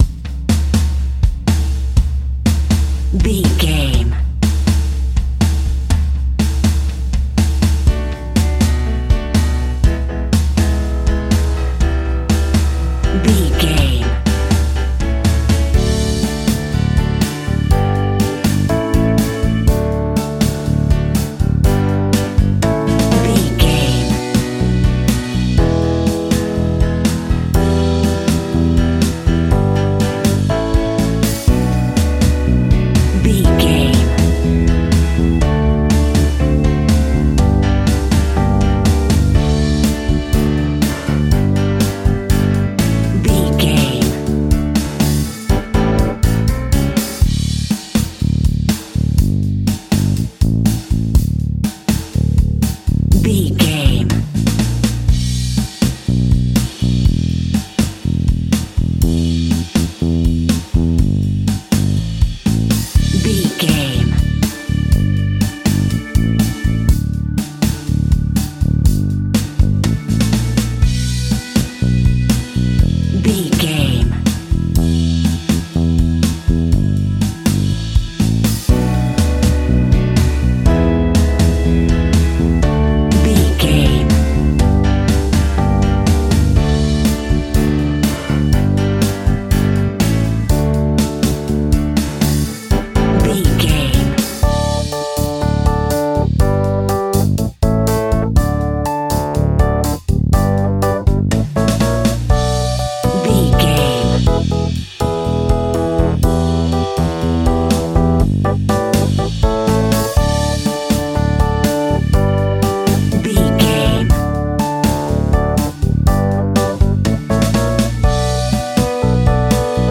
Ionian/Major
cheesy
bubblegum
electro pop
pop rock
synth pop
bold
happy
peppy
upbeat
bright
bouncy
drums
bass guitar
electric guitar
keyboards
hammond organ
acoustic guitar
percussion